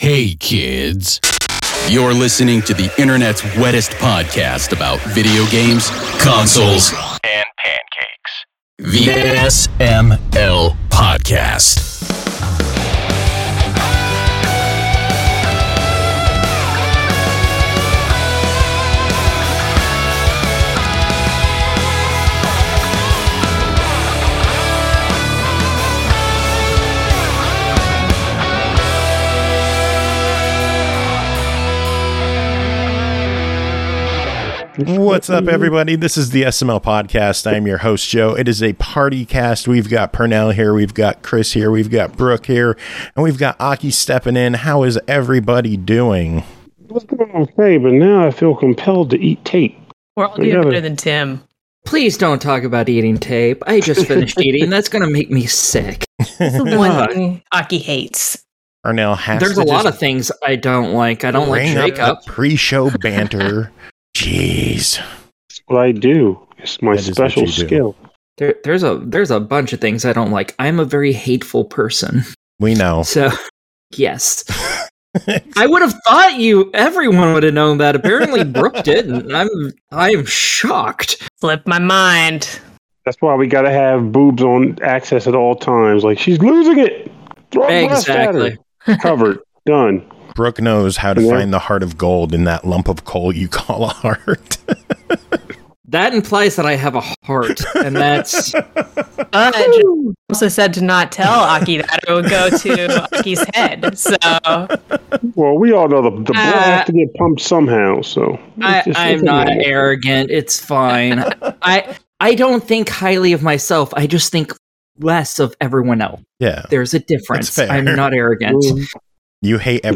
It’s a PartyCast packed with reviews and chatter, so let’s get the show on the road!